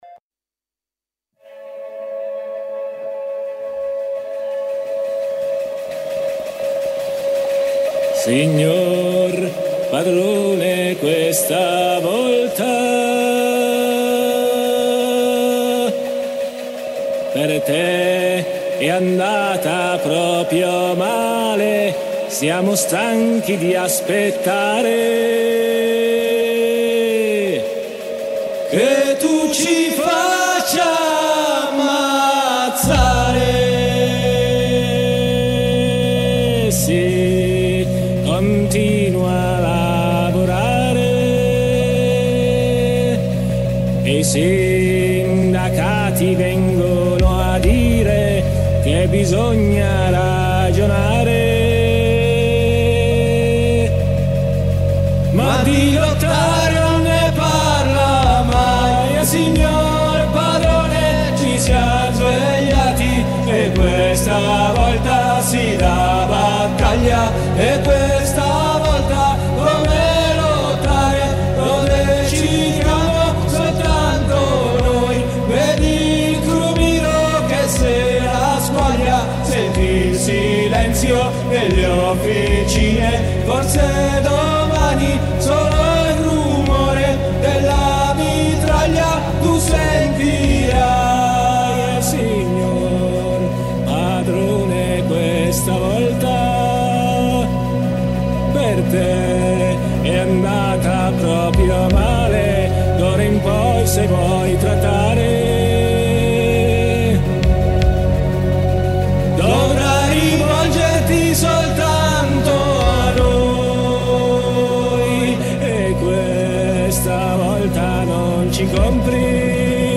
La serata è impreziosita dalla colonna sonora dal vivo a cura del Nuovo Canzoniere Elettronico.